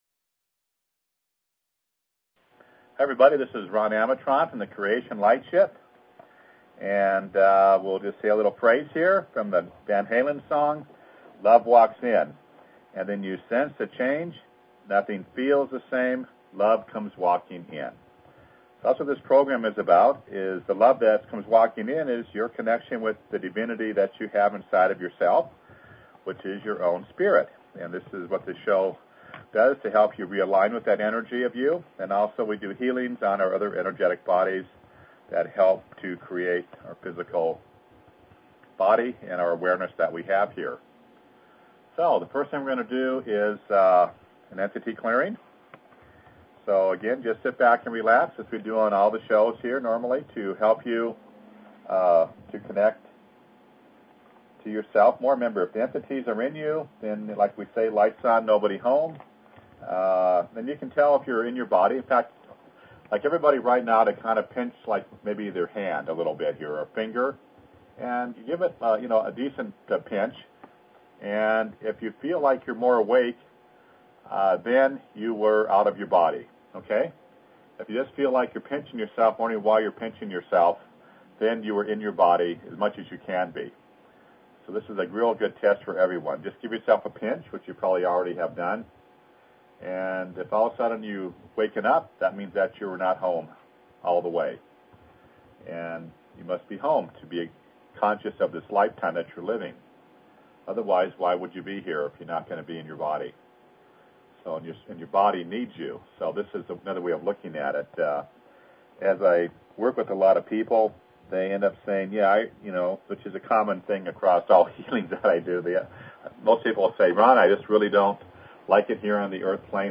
Talk Show Episode, Audio Podcast, Creation_Lightship_Healings and Courtesy of BBS Radio on , show guests , about , categorized as